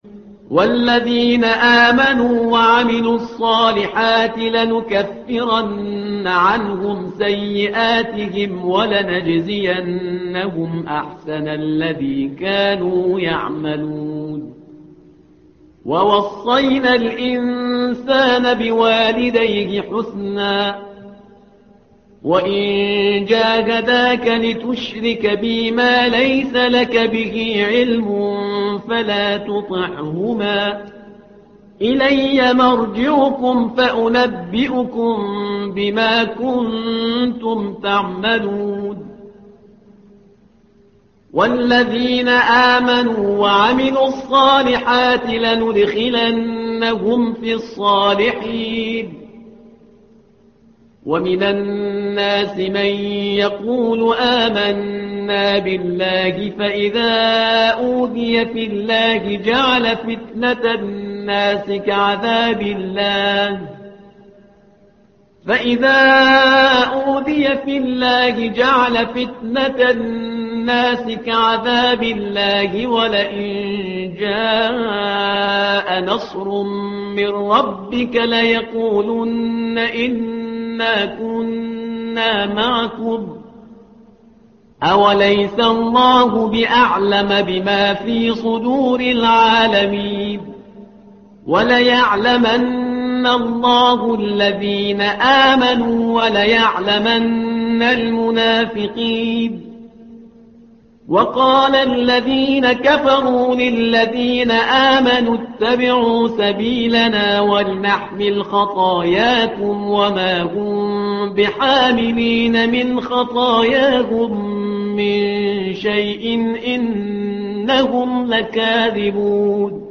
تحميل : الصفحة رقم 397 / القارئ شهريار برهيزكار / القرآن الكريم / موقع يا حسين